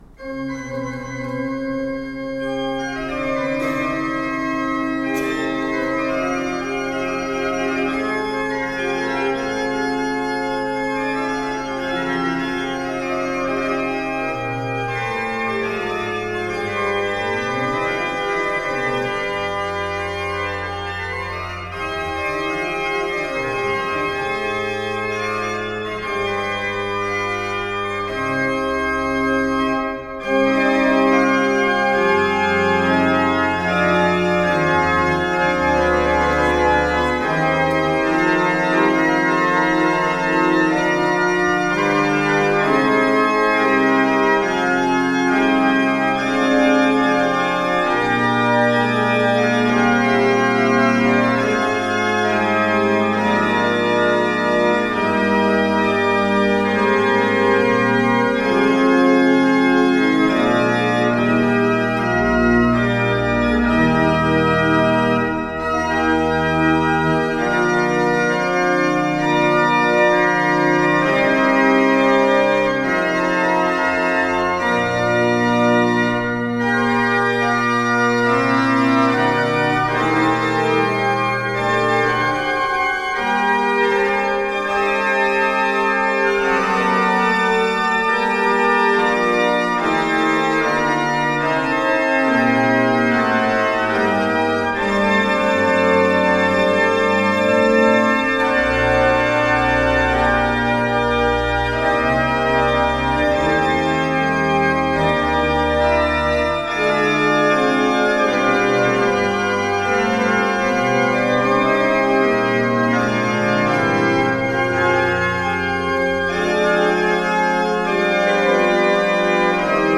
- Les concerts passés à l'église de Domgermain - Entrée libre -
Concert